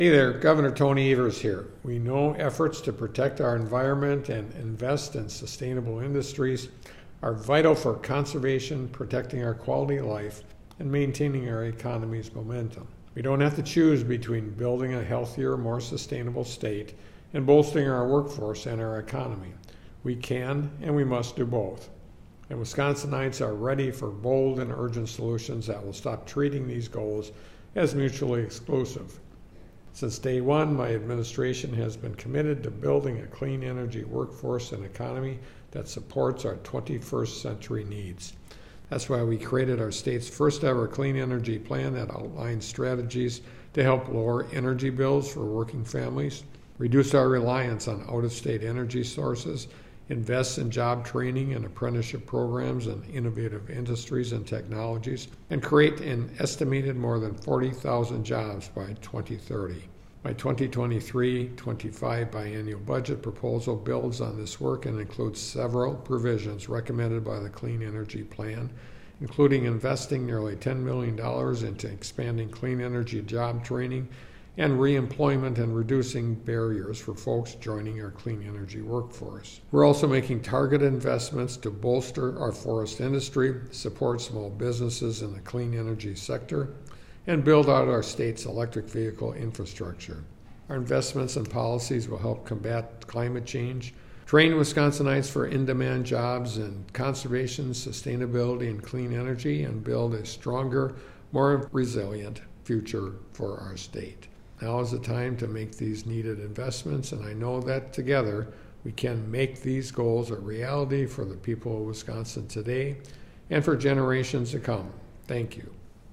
MADISON — Gov. Tony Evers today delivered the Democratic Radio Address highlighting his budget initiatives to bolster Wisconsin’s clean energy workforce and economy.